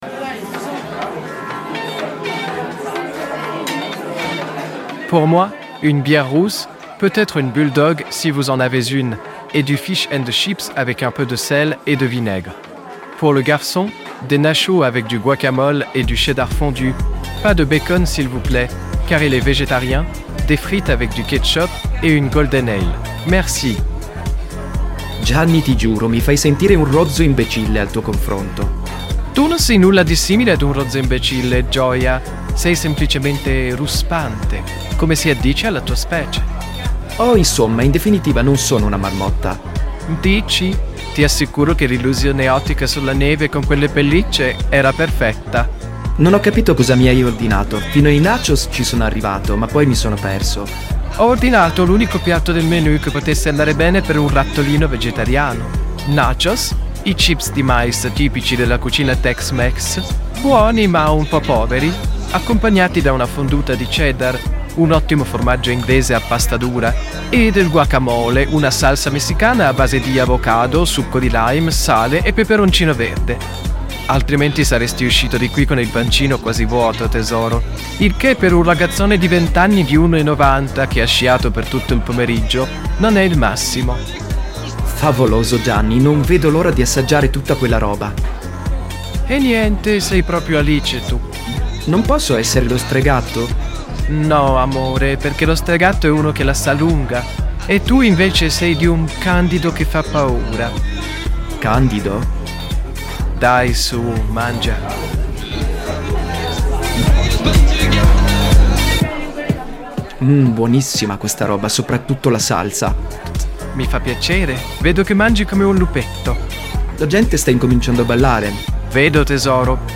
Nel corso dell'episodio si possono ascoltare brani e cover tratti da "Music Sounds Better With You" (Stardust), "We Can Build A Fire" (Autheart) e "Back" (Bad Boys Blue).
During the episode you can listen to songs and covers from "Music Sounds Better With You" (Stardust), "We Can Build A Fire" (Autheart) and "Back" (Bad Boys Blue).